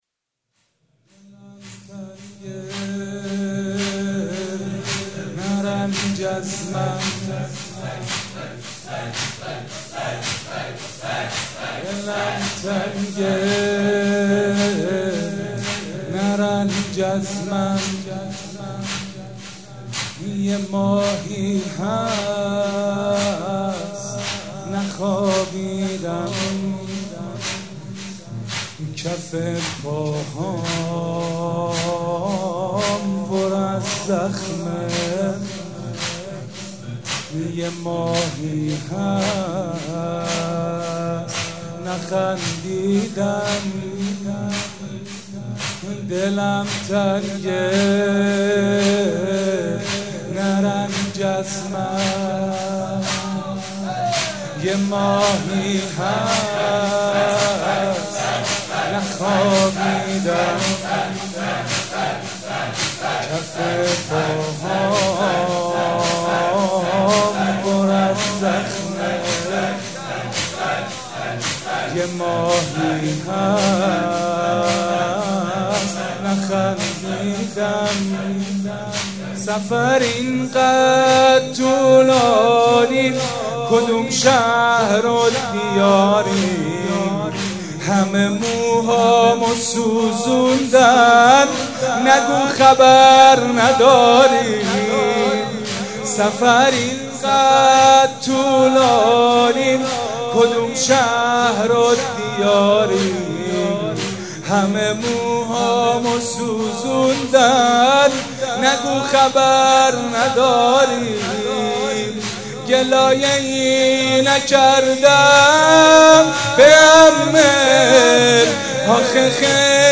مراسم عزاداری شب سوم